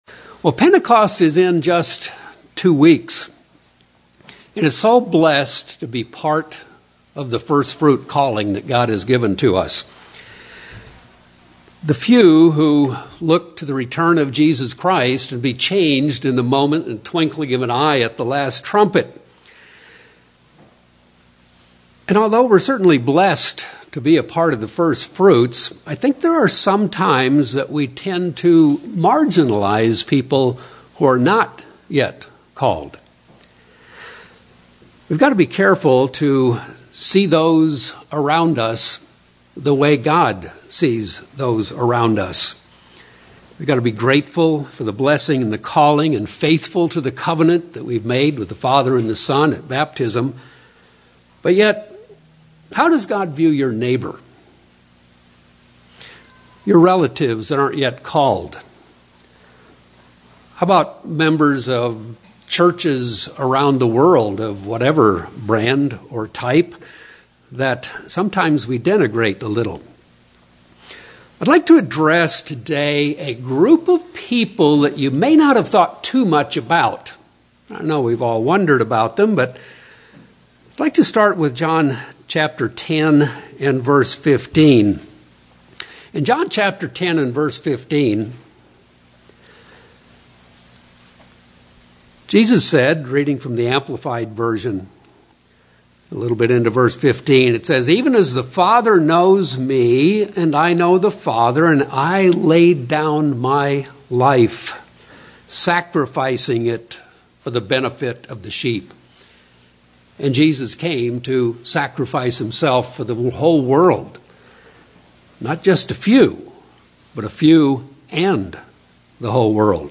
Sermons
Given in San Diego, CA